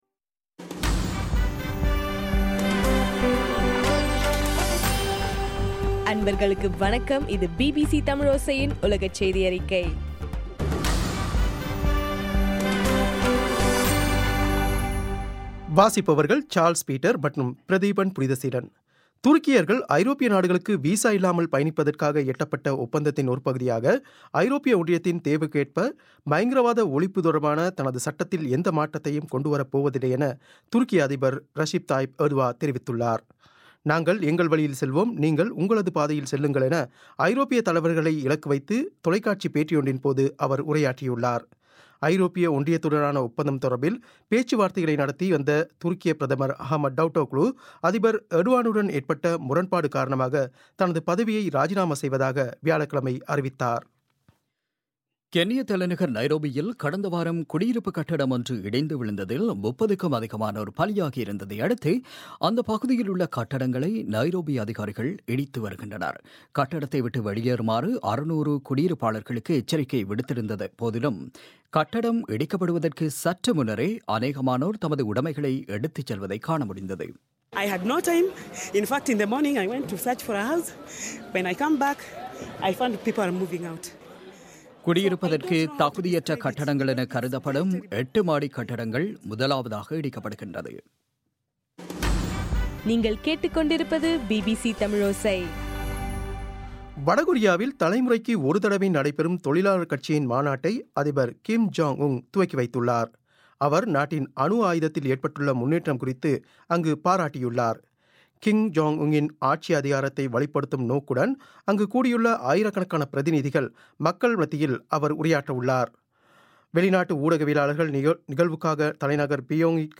மே 6 பிபிசியின் உலகச் செய்திகள்